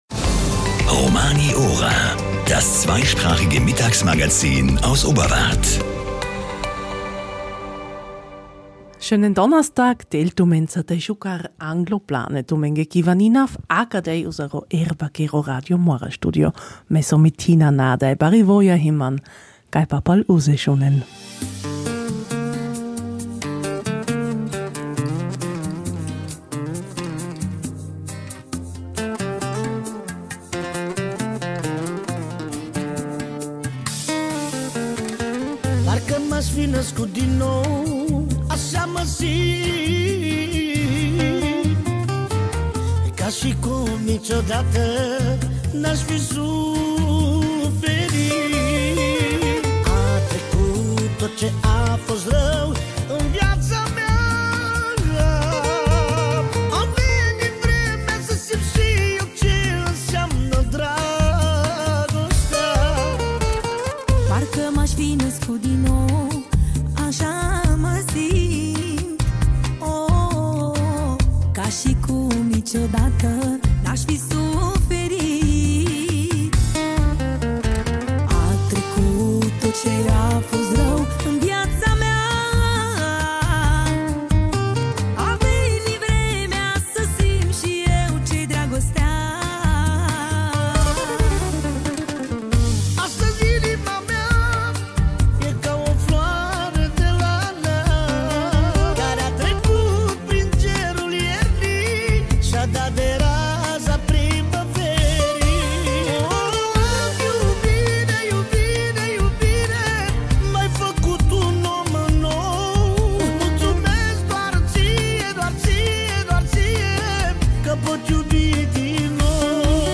Die Freitagsausgabe der „Romani Ora“ versorgt Sie wieder mit den Veranstaltungshinweisen/ Mulatintschage, der abwechslungsreichsten Roma-Musik und einem Beitrag zum Thema „Osterpinzen backen“.